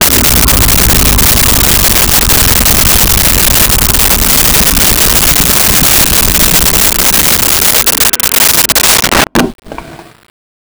Creature Growl 04
Creature Growl 04.wav